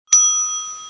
bouton_ding